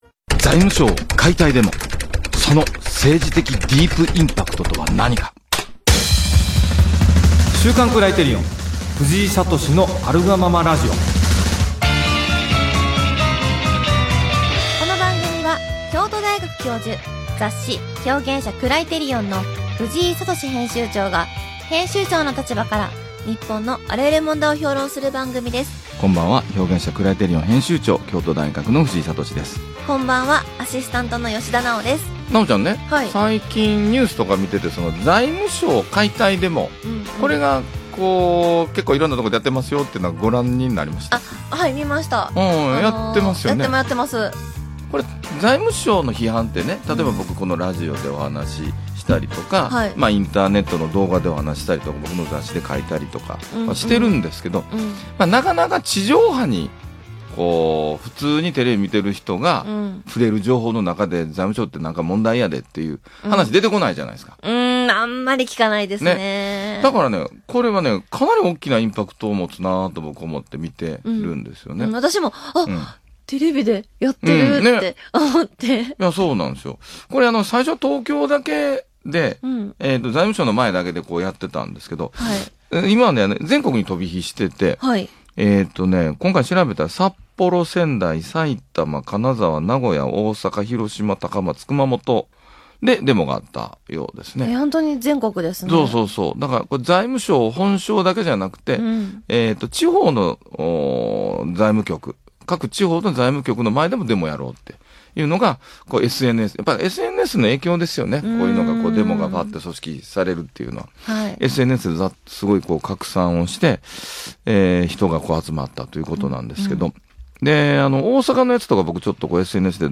【ラジオ】「財務省解体デモ」，その政治的ディープインパクトとは何か？